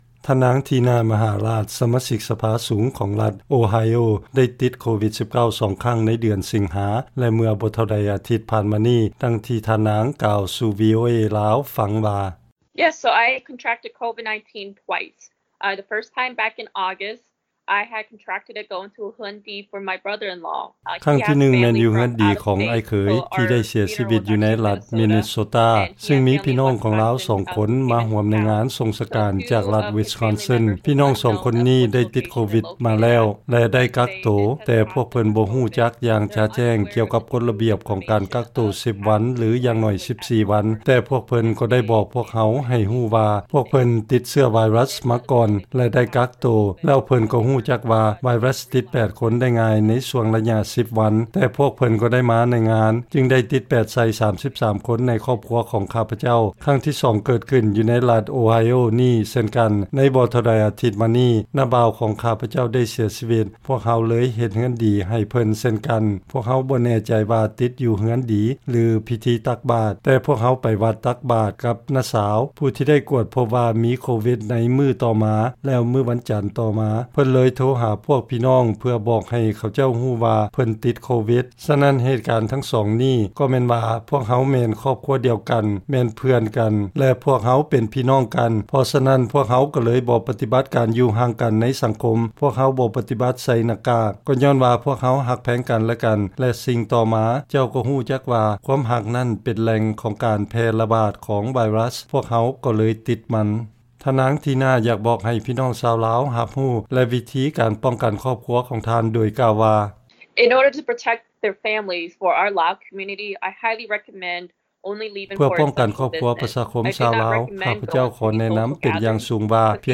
ລາຍງານຂ່າວ ທ່ານນາງ ທີນາ ມະຫາຣາດ ສະມາຊິກສະພາສູງ ລັດໂອໄຮໂອ ຕິດໂຄວິດ-19 ສອງເທື່ອ